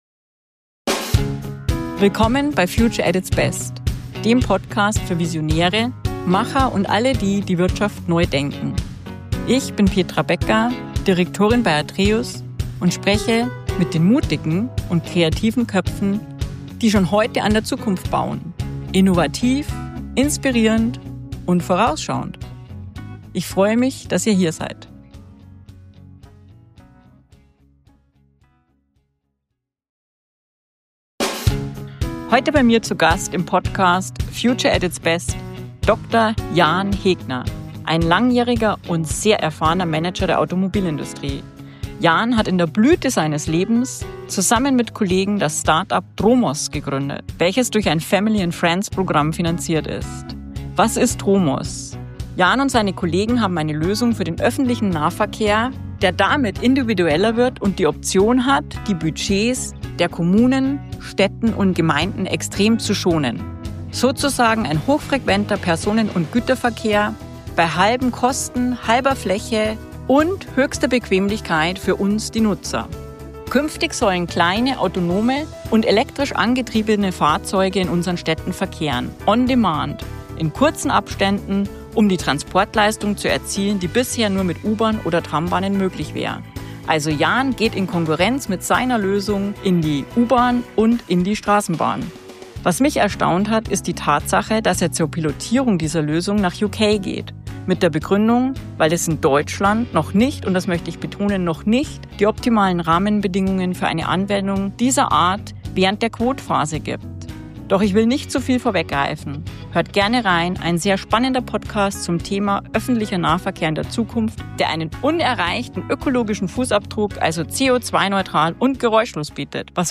Ein Gespräch über radikale Ideen, realistische Umsetzungen und warum der ÖPNV nicht nur modernisiert, sondern neu erfunden werden muss.